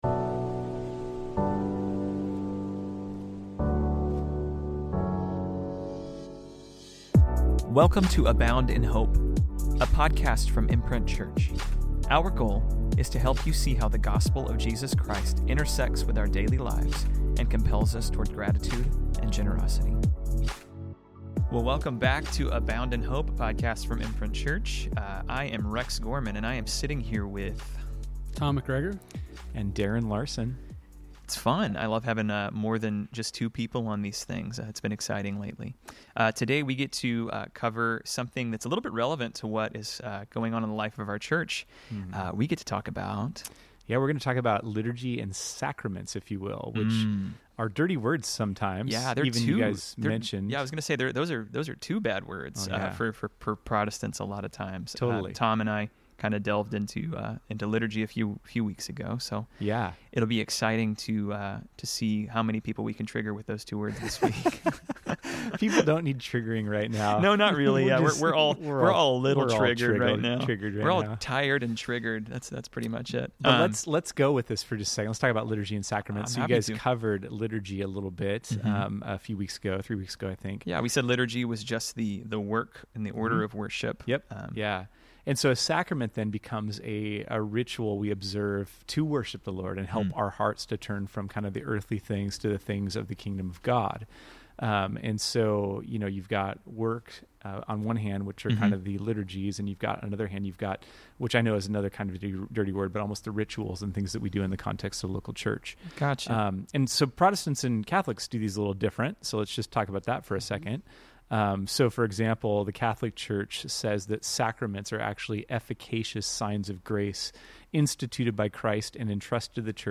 Listen to the first part of a two part conversation on Sacraments.